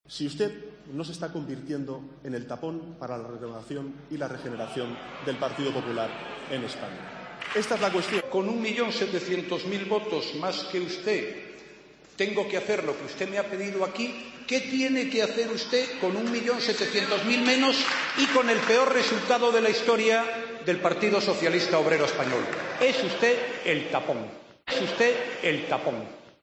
Escucha el rifirrafe entre Sánchez y Rajoy por ver quién es "el tapón"